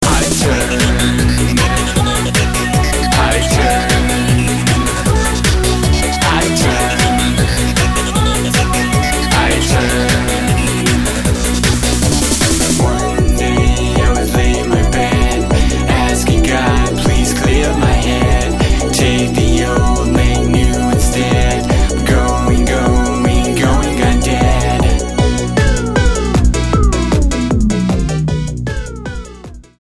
STYLE: Dance/Electronic
synth-pop trio